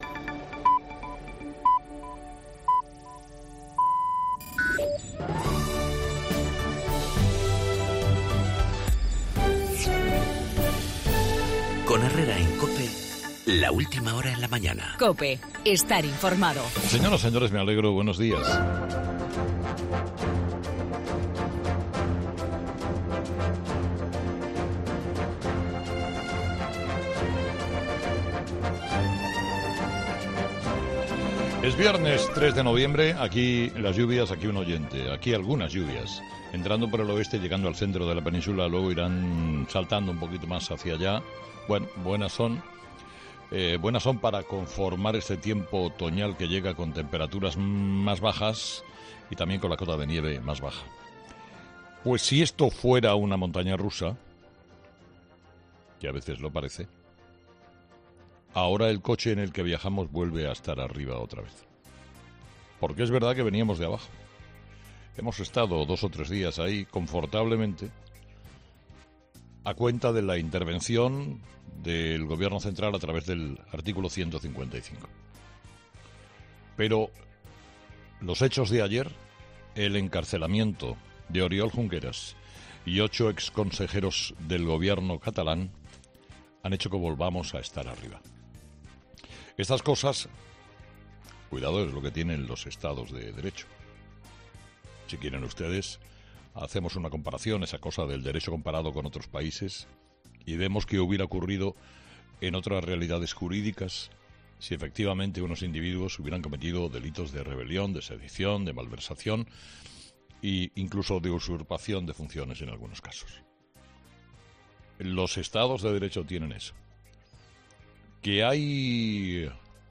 La entrada en prisión del exvicepresidente catalán y otros ocho exconsejeros, en el editorial de Carlos Herrera